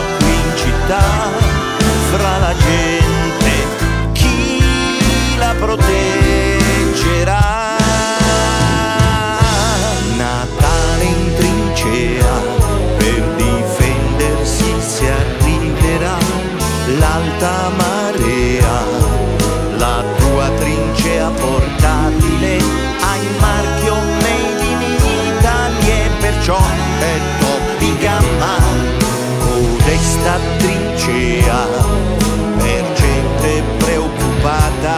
Жанр: Поп / Рок